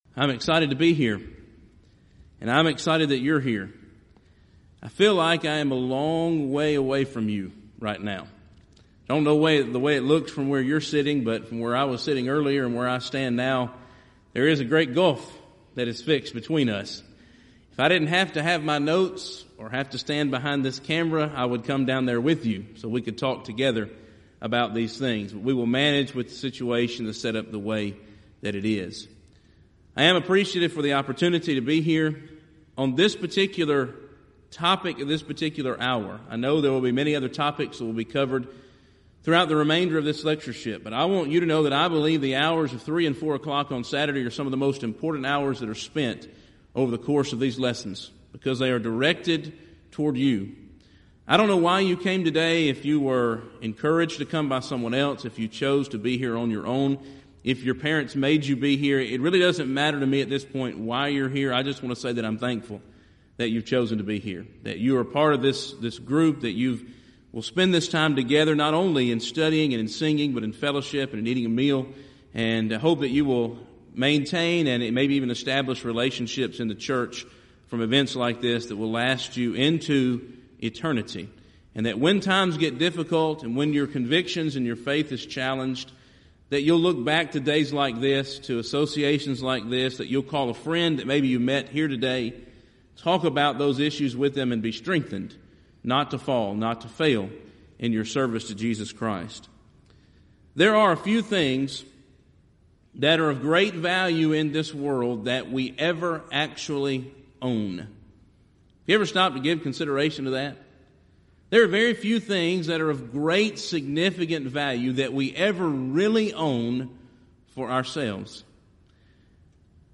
Event: 30th Annual Southwest Bible Lectures
If you would like to order audio or video copies of this lecture, please contact our office and reference asset: 2011Southwest22